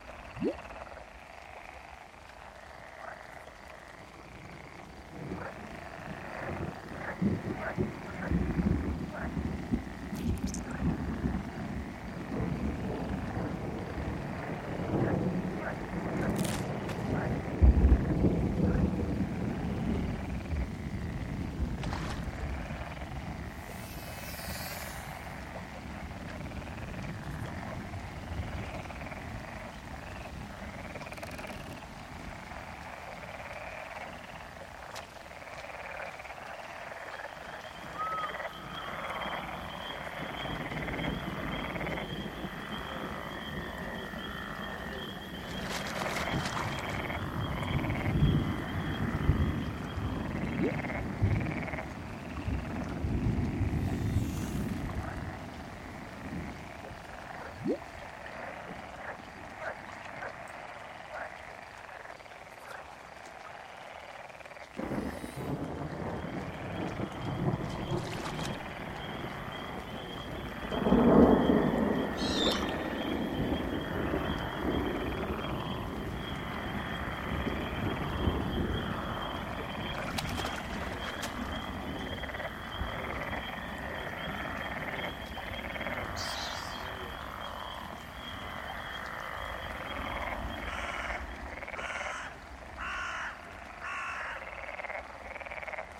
Звуки болота
Атмосферные звуки болота в зацикленном варианте